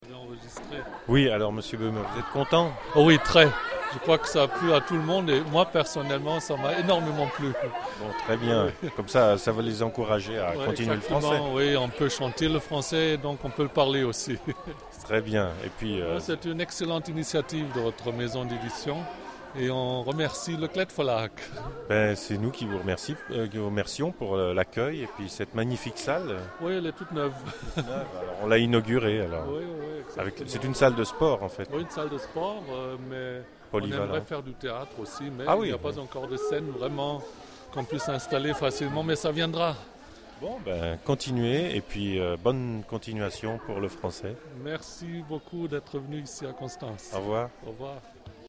Interview mit dem Schulleiter